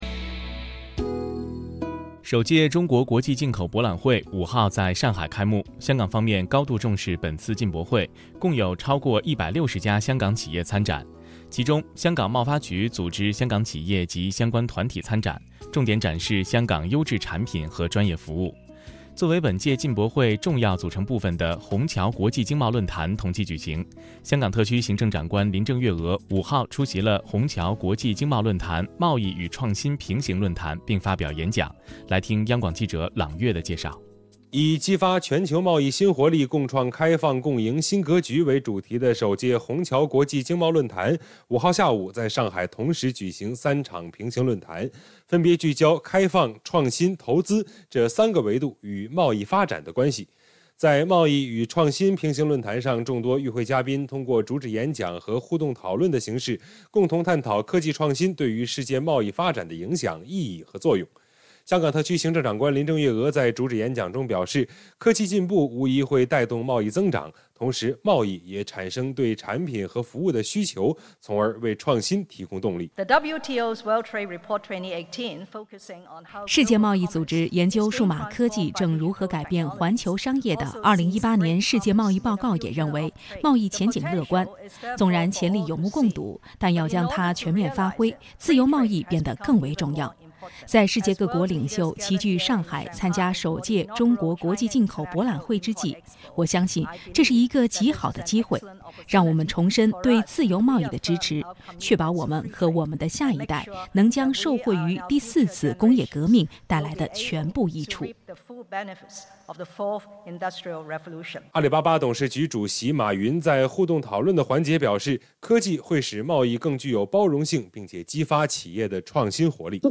随着两地交流日益扩大，驻京办加强了在华北地区的宣传和推广工作，并自2006年起与中央人民广播电台「华夏之声」（2019年9月起更名为中央广播电视总台大湾区之声）携手打造普通话广播节目「每周听香港」，在华北九个省、市、自治区级电台播出，以趣味与信息并重的形式，把香港的最新发展带给当地听众。